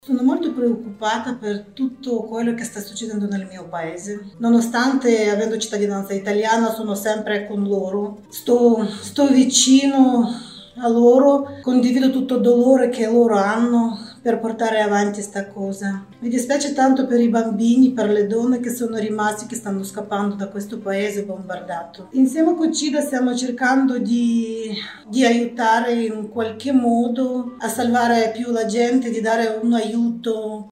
Ascoltiamo una testimonianza.